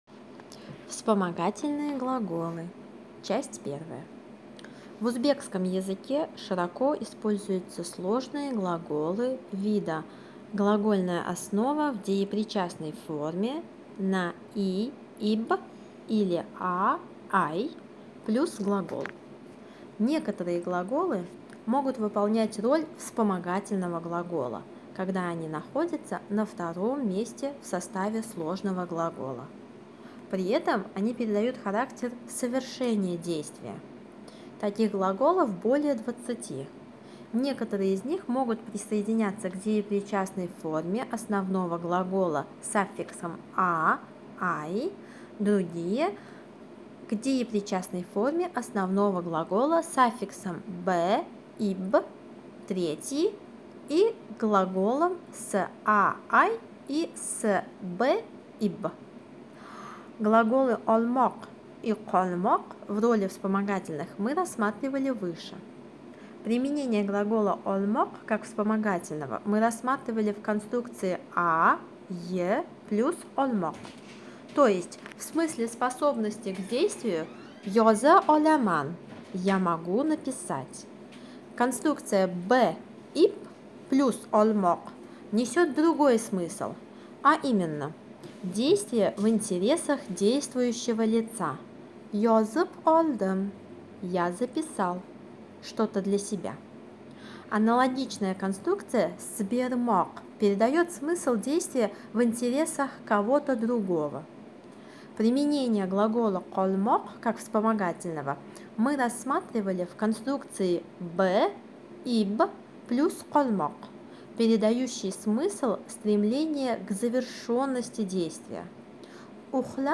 Узбекский язык - аудиоуроки